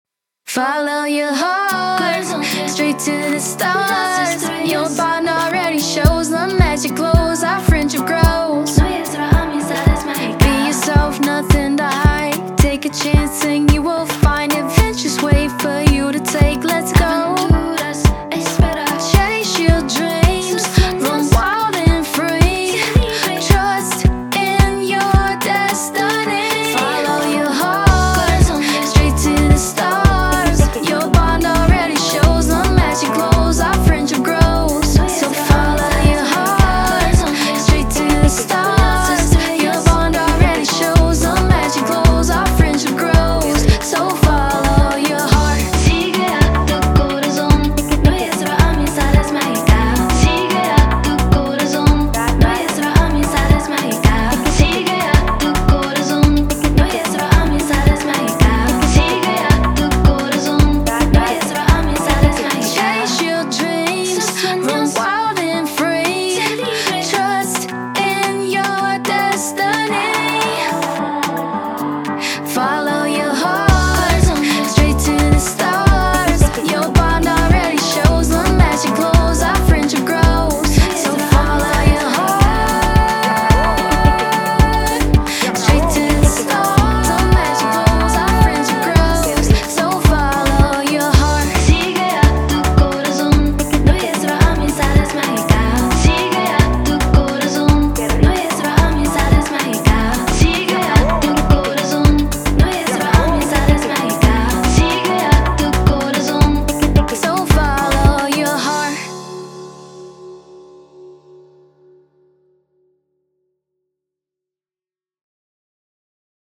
Трек размещён в разделе Зарубежная музыка / Поп.